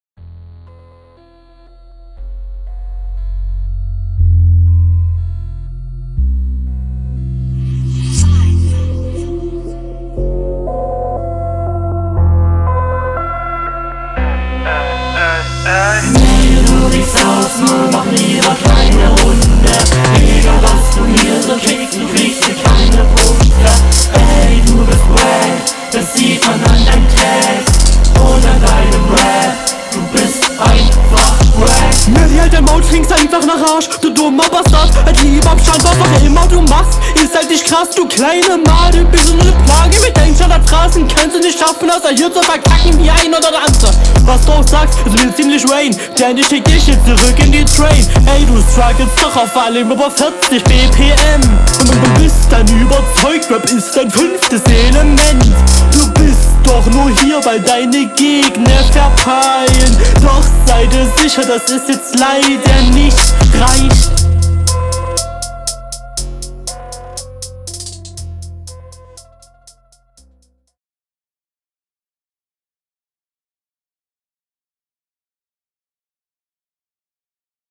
Hinrunde 1
Puh die Hook klingt sehr schlecht, ich versteh einerseitz nichts und das autotune ist komplett …
Flow: Die triolischen Passagen sitzen nicht wirklich tight auf dem Beat. Der Stimmeinsatz passt soweit.